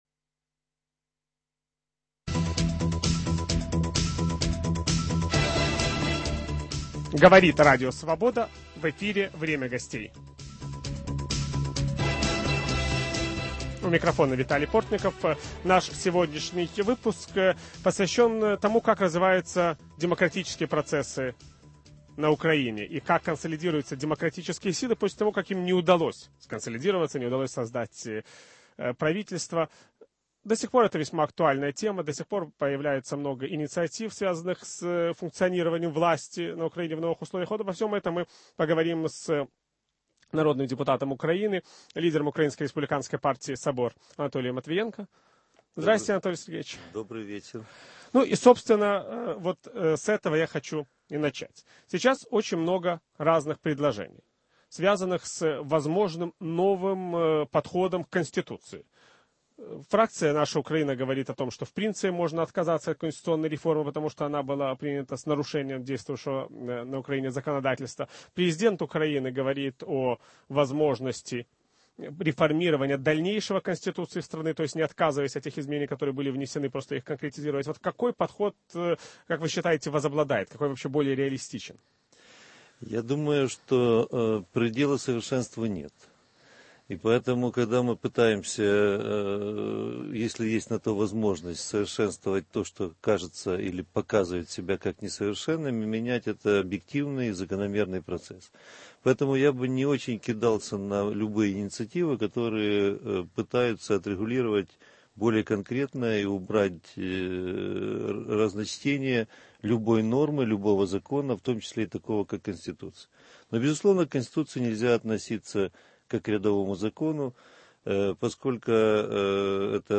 В киевской студии Радио Свобода - народный депутат Украины, лидер Украинской республиканской партии "Собор" Анатолий Матвиенко.